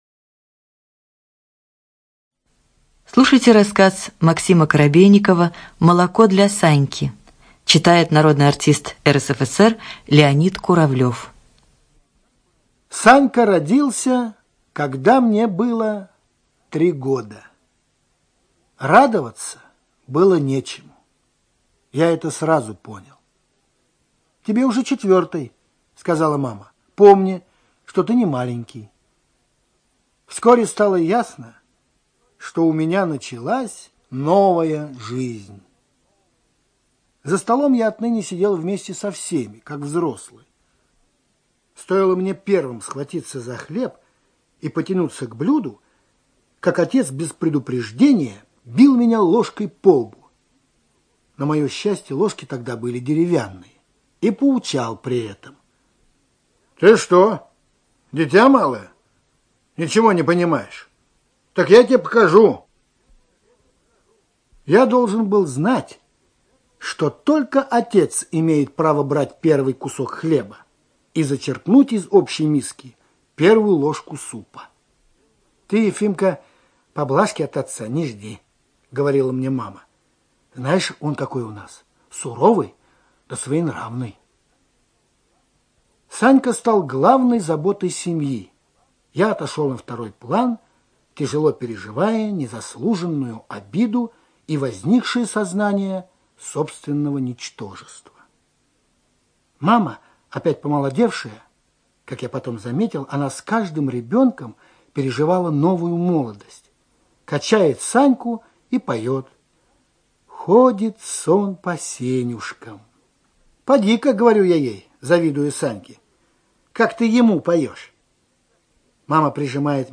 ЧитаетКуравлев Л.